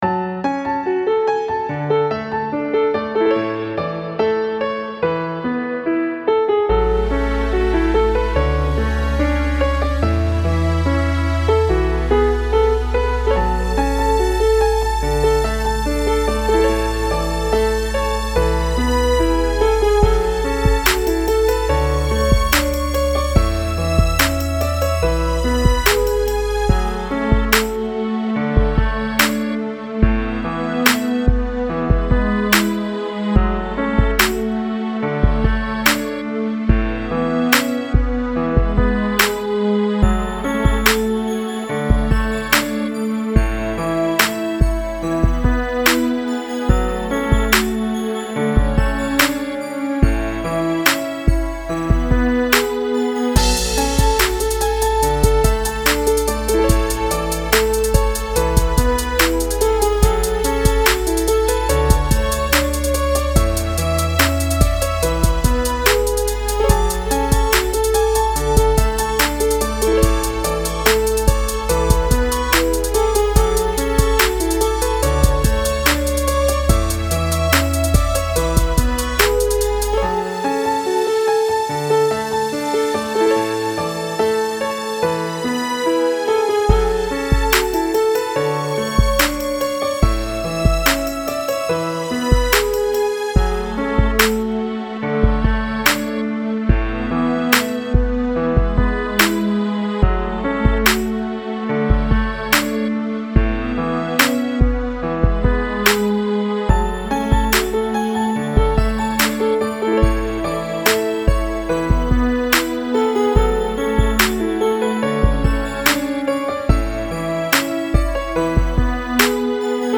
современная обработка